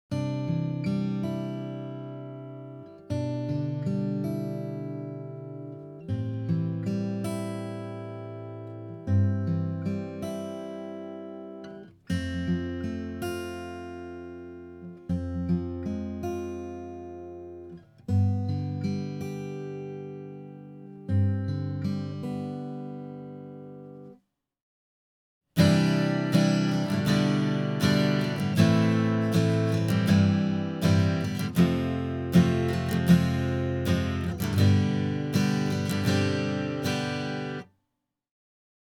・すべて同じマイクセッティングで録音（AKG C3000使用）
・演奏するフレーズは毎回同じように弾けるように極簡単なフレーズを採用
・録音ファイルには一切の加工はしない
アコギD
D_guitar.wav